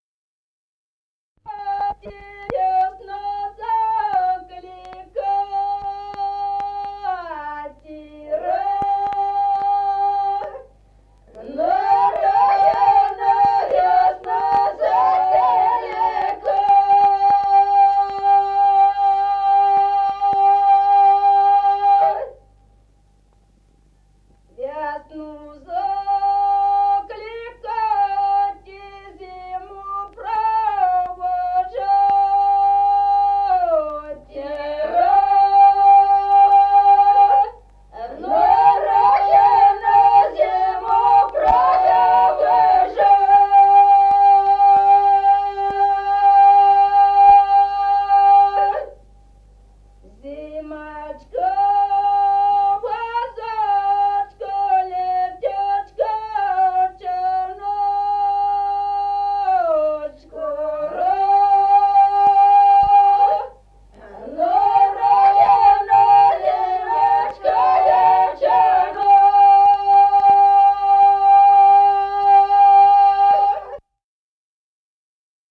Песни села Остроглядово. Благослови, мати (весновая).